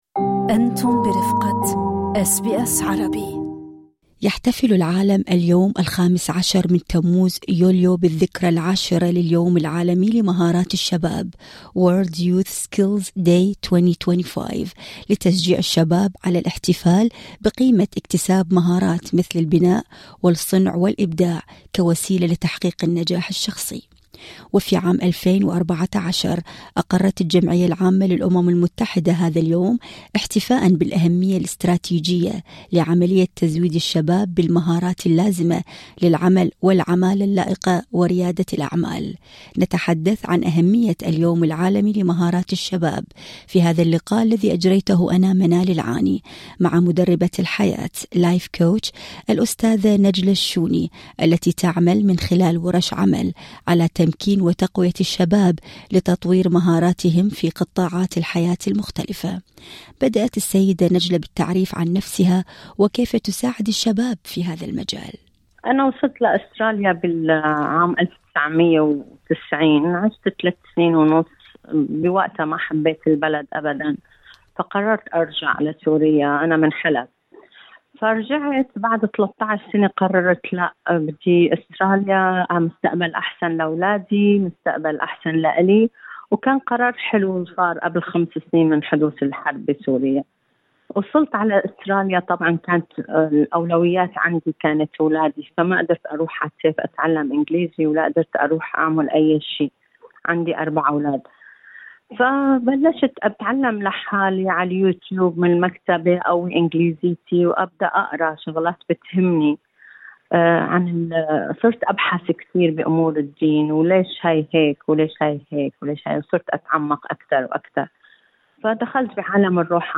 نتحدث عن أهمية اليوم العالمي لمهارات الشباب في هذا اللقاء